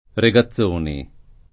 [ re g a ZZ1 ni ]